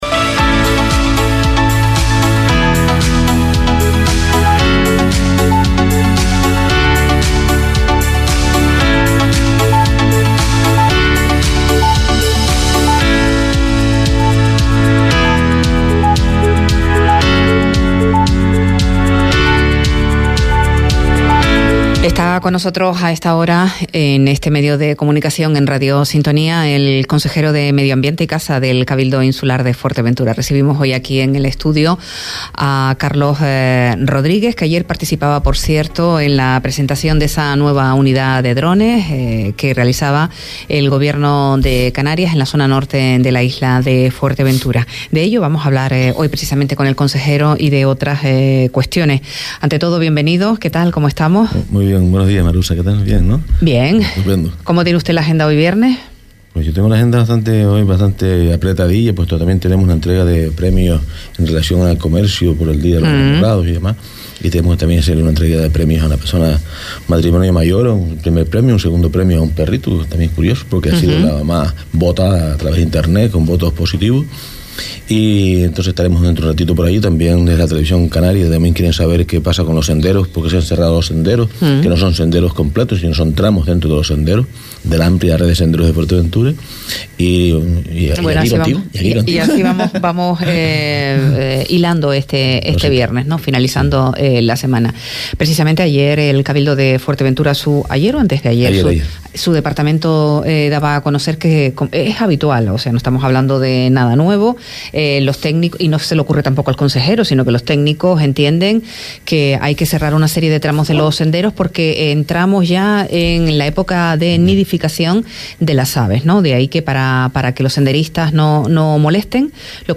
Entevista a Carlos Rodríguez, consejero de Medioambiente del Cabildo de Fuerteventura – 16.02.24
Entrevistas